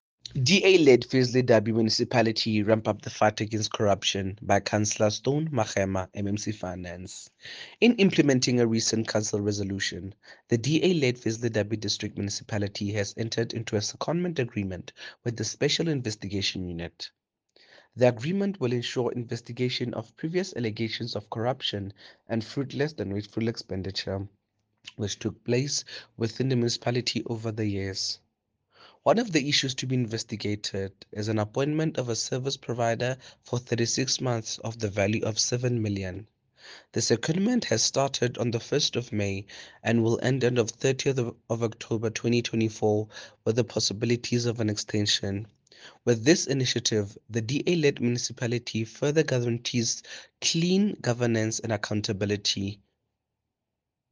English soundbite by Cllr Teboho Thulo and